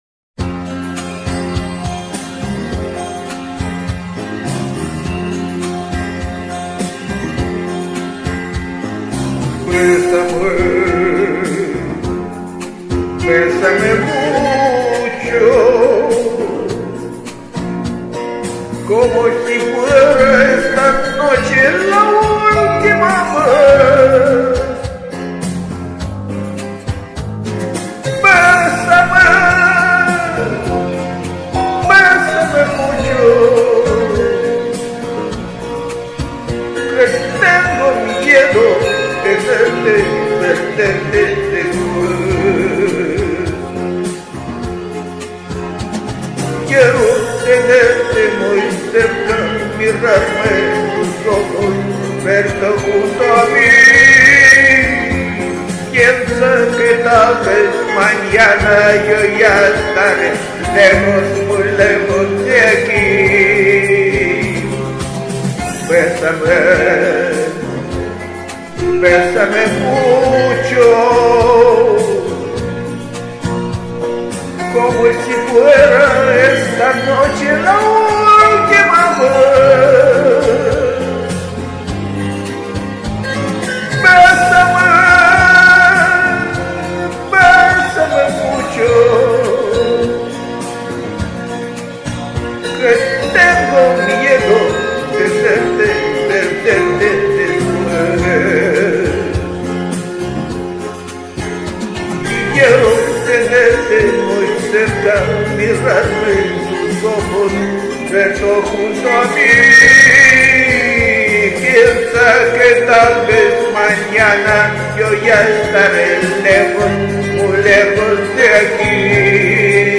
Музыкальный хостинг: /Шансон